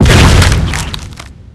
Index of /cstrike_backup/sound/knifes/hammer
hit_normal_2.wav